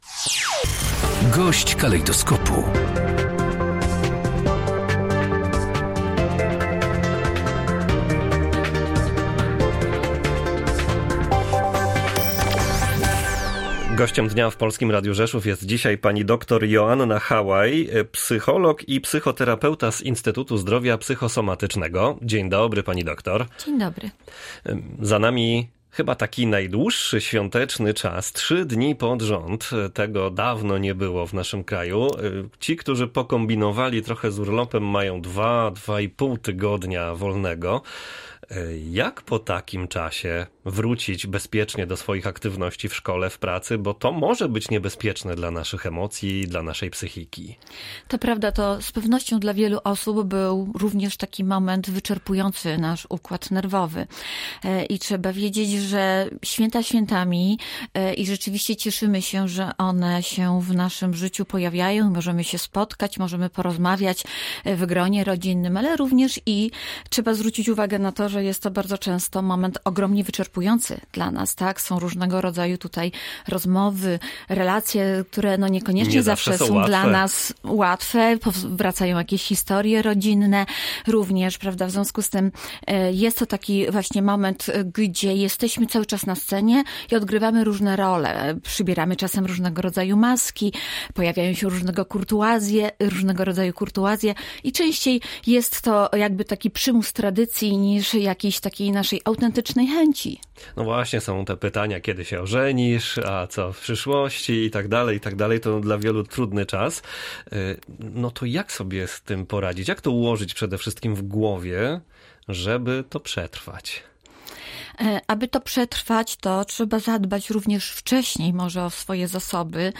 • Gość dnia • Polskie Radio Rzeszów